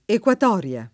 [ ek U at 0 r L a ]